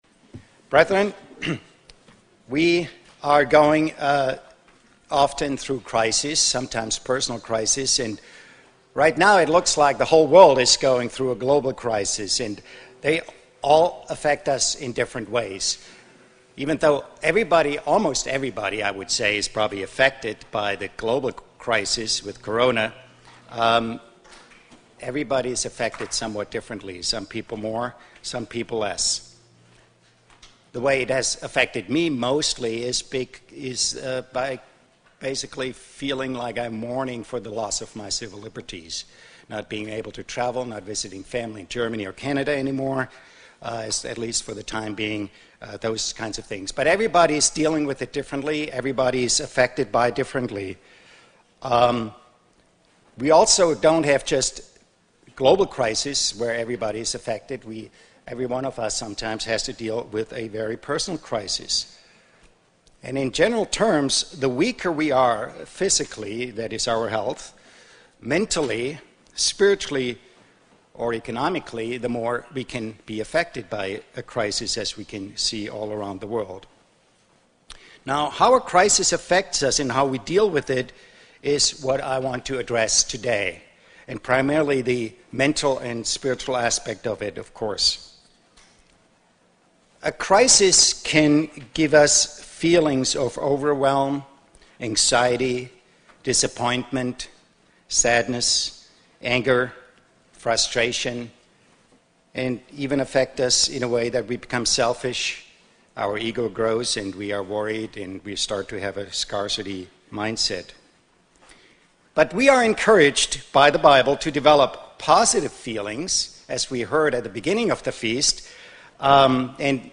Sermons
Feast of Tabernacles 2020 - Berlin, OH